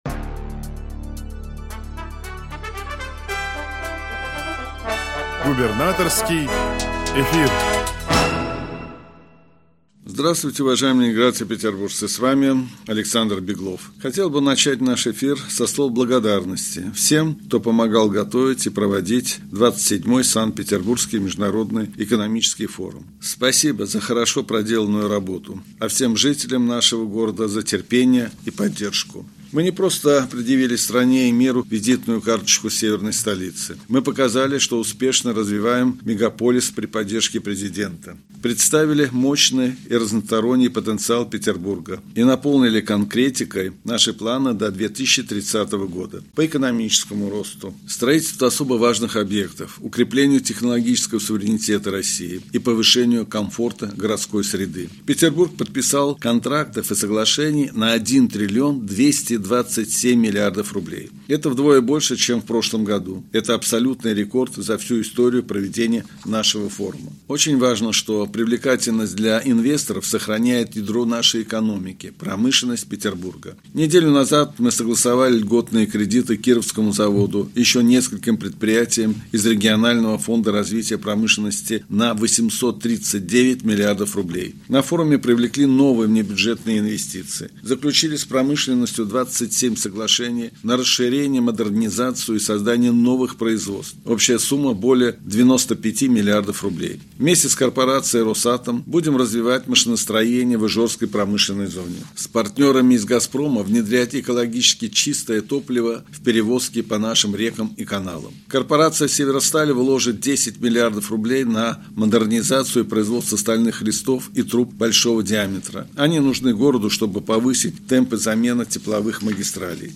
Радиообращение – 10 июня 2024 года